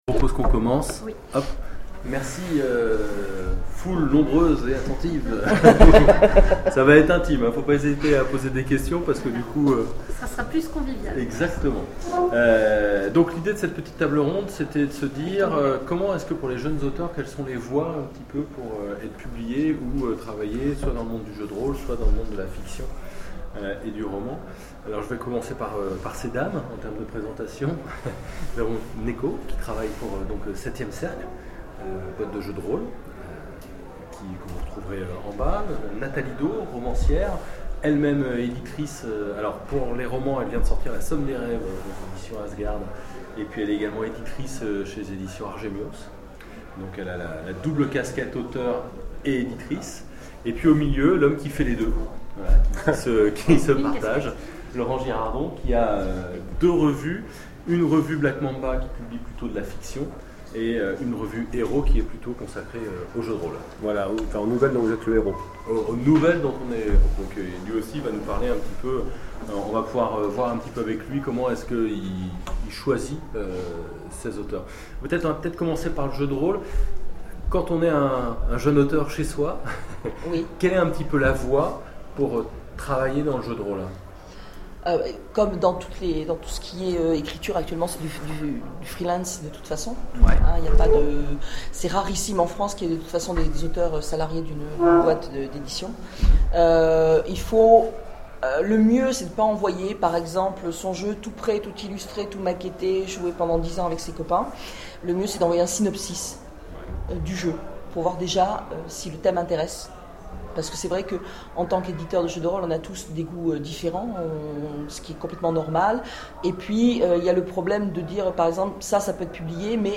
Octogones 2012 : conférence Auteurs de l'imaginaire (livres, jeux,...) : la longue route vers l'édition
Mots-clés Ecriture Jeu de rôle Conférence Partager cet article